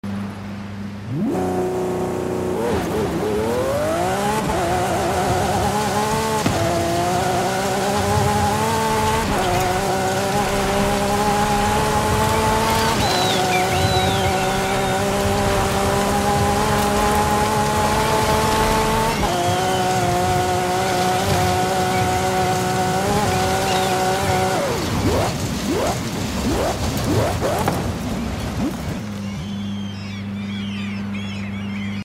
2021 Lamborghini Countach LPI 800 4 Sound Effects Free Download